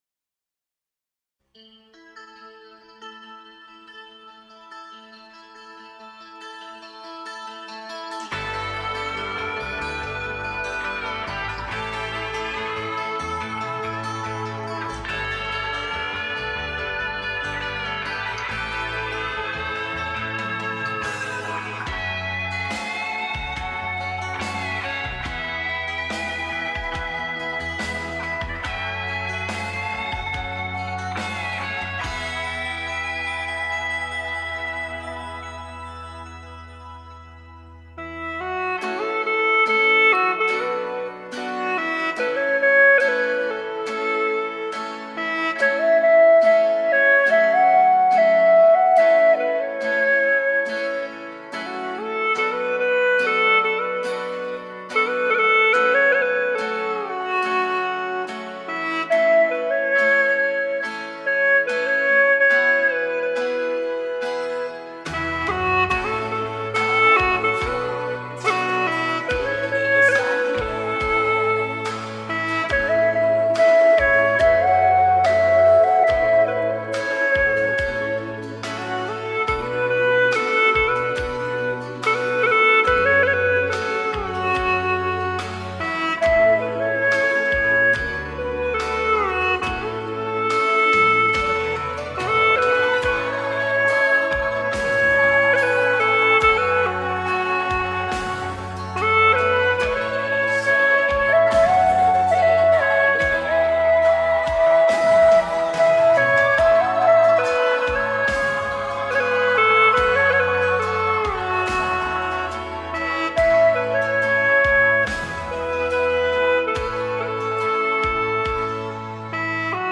曲类 : 流行
【A转降B调】